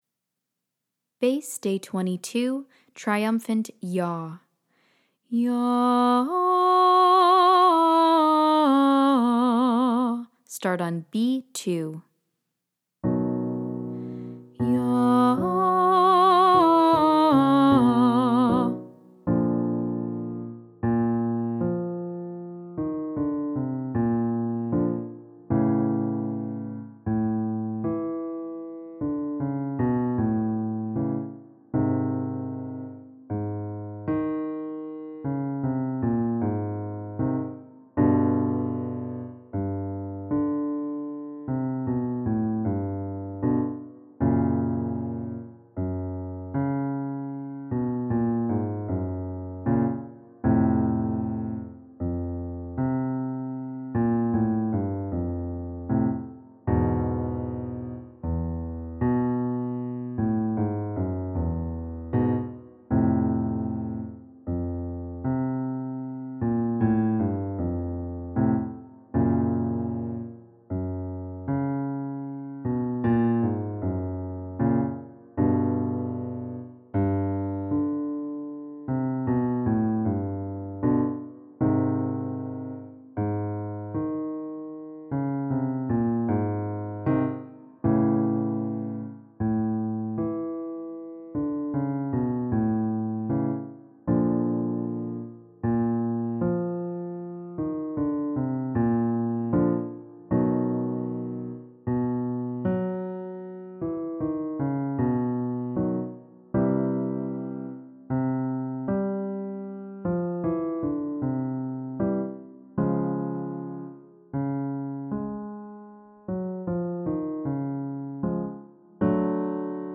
Day 22 - Alto - Vibrato Practice